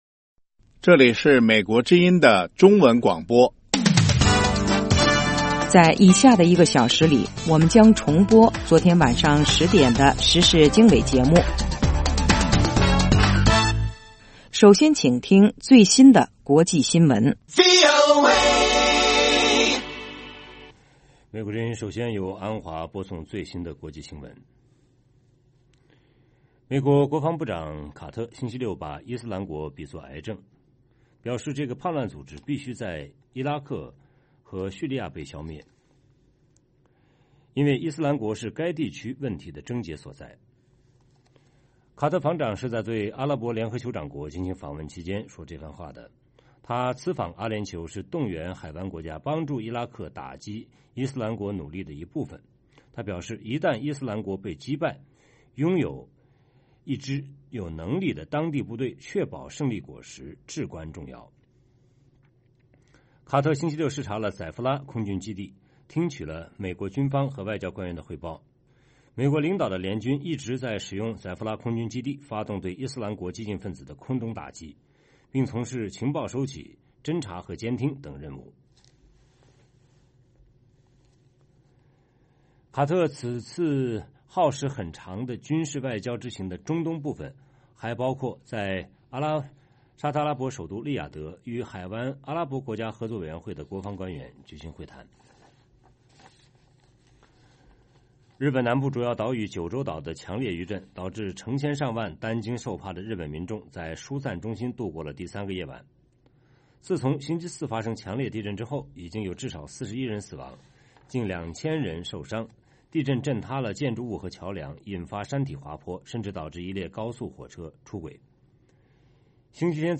北京时间早上6-7点广播节目 这个小时我们播报最新国际新闻，并重播前一天晚上10-11点的时事经纬节目。